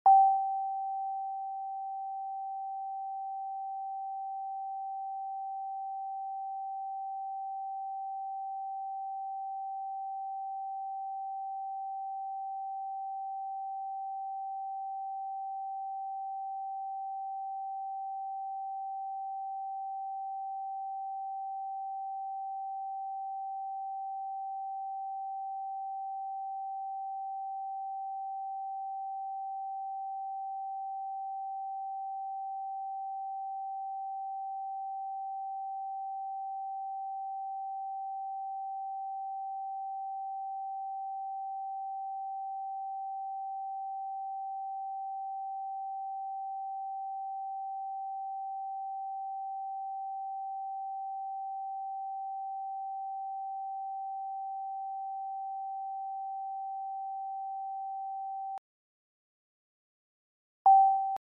777 Hz Frequency Meditation.